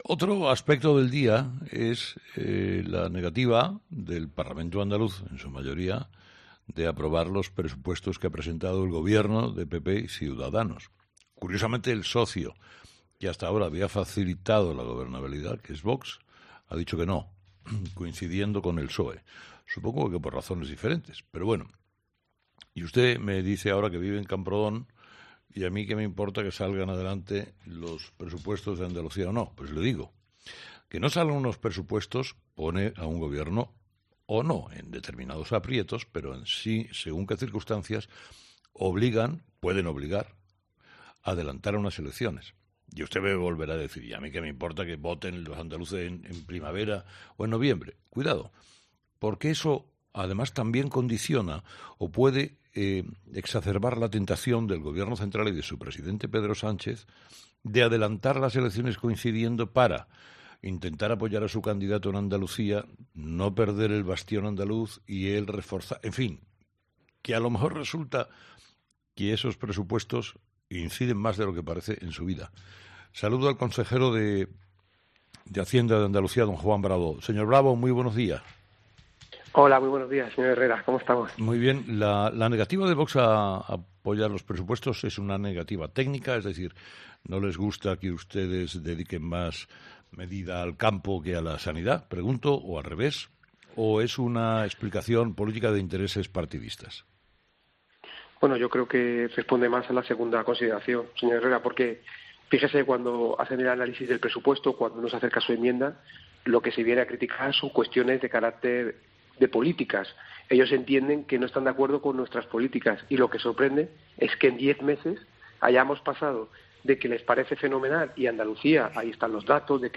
Juan Bravo es Consejero de Hacienda y Financiación Europea de la Junta de Andalucía, y en esta mañana nos ha puesto al día en Herrera en COPE, para entre otras cosas, tratar de aclarar si puede reabrirse la posibilidad de que se produzcan unas elecciones antes de lo previsto.